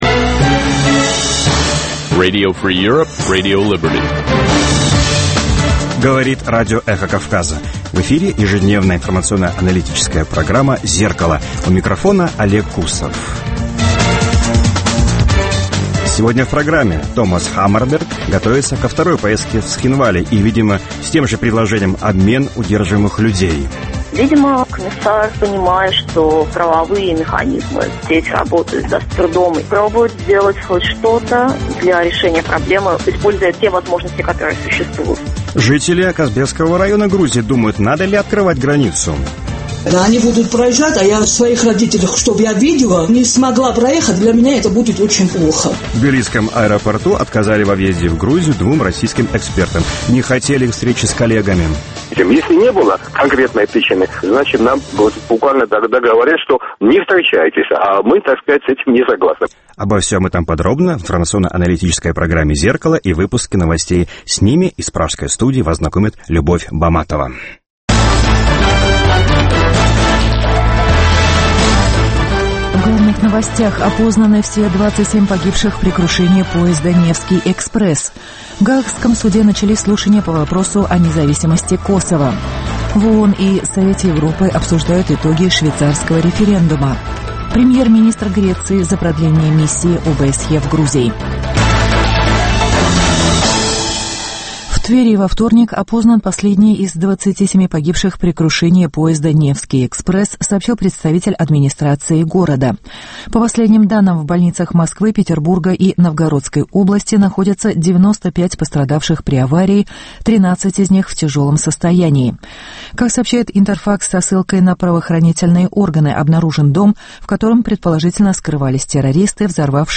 Новости, репортажи с мест, интервью с политиками и экспертами , круглые столы, социальные темы, международная жизнь, обзоры прессы, история и культура.